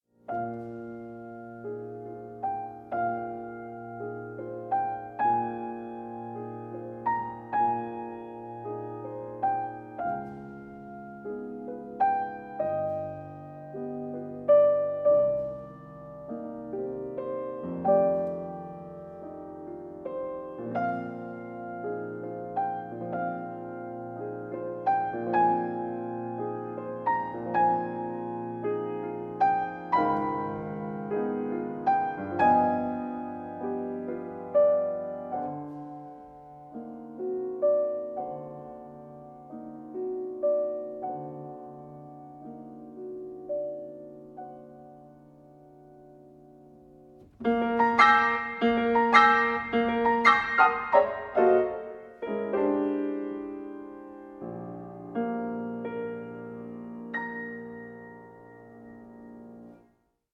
Stereo
piano (Steinway D)
Recorded February 2017 at Milton Court, London UK